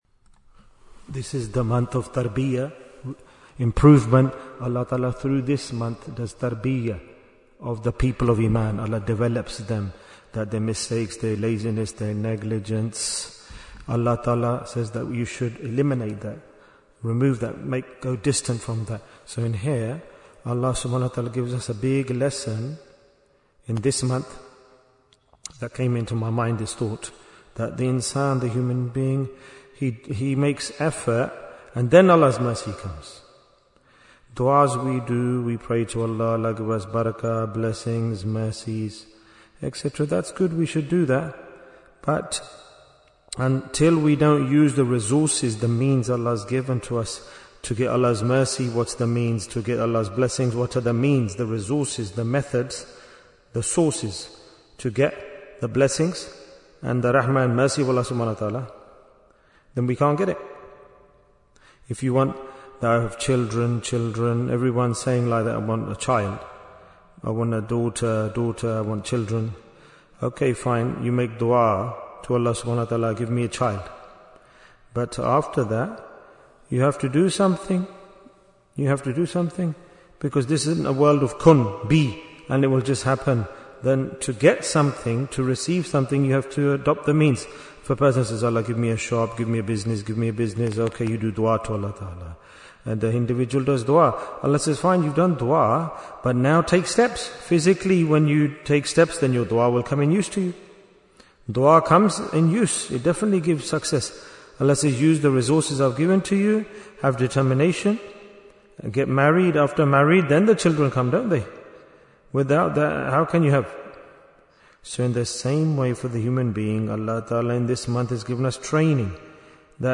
Jewels of Ramadhan 2026 - Episode 4 Bayan, 34 minutes19th February, 2026